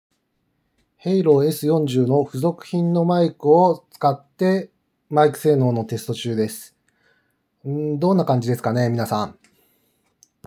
付属品の専用マイクを使うとどうなるのか検証しました。
マイク性能自体に変化はないがマイクとの距離が近いので雑音が入りにくくなっています。